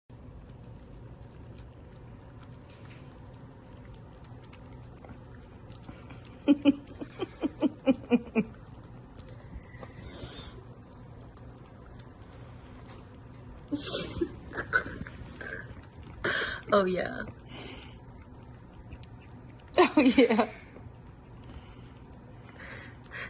ASMR Sound Effects Free Download